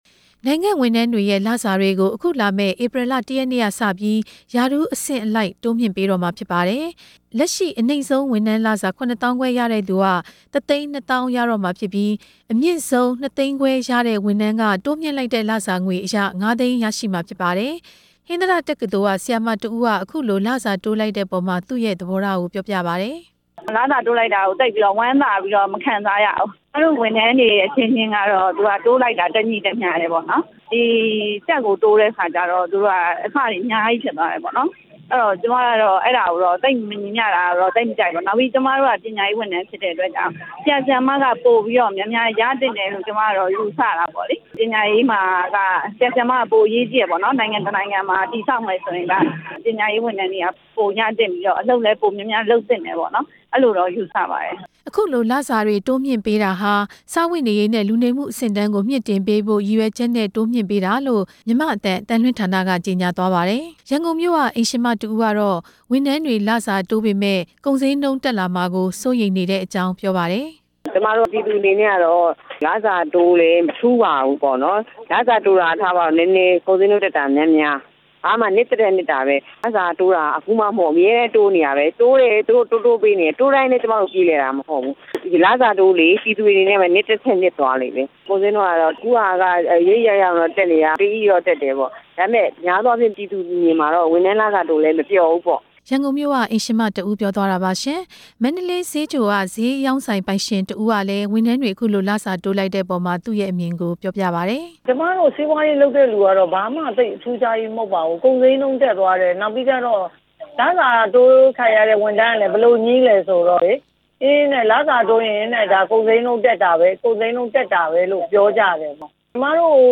တင်ပြချက်